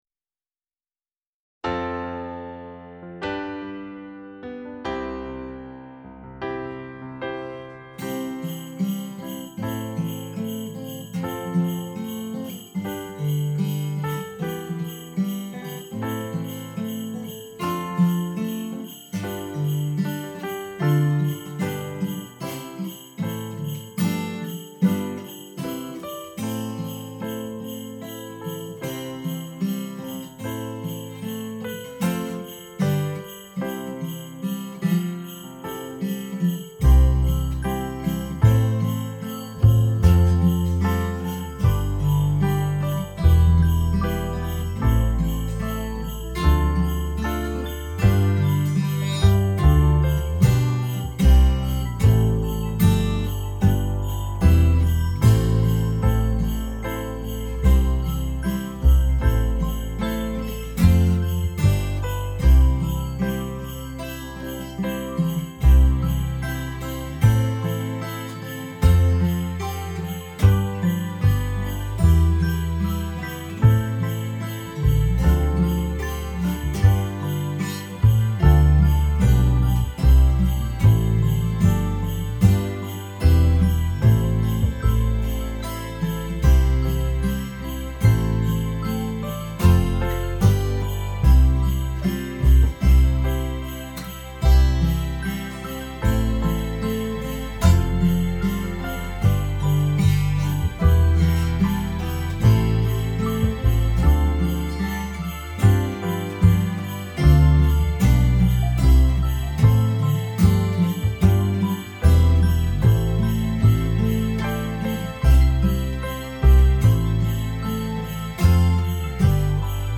Weihnachtssterne_Playback.mp3